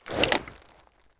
holster.wav